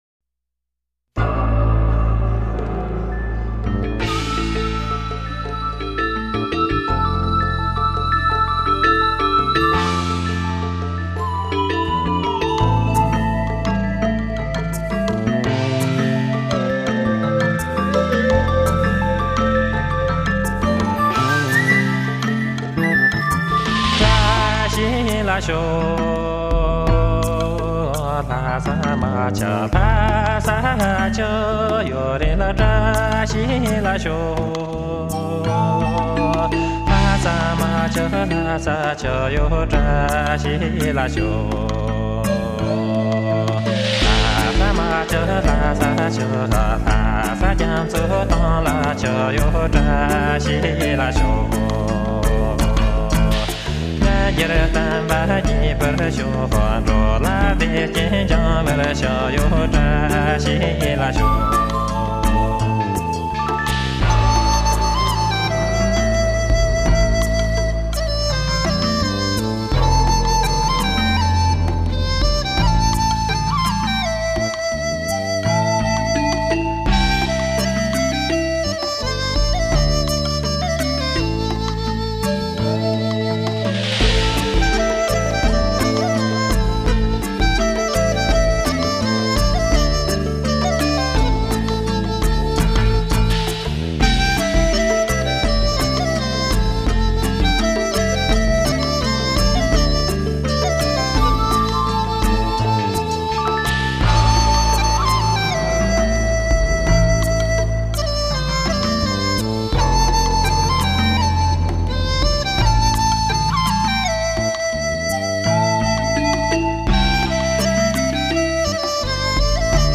Tibetan Folk Music
笛、笙
胡琴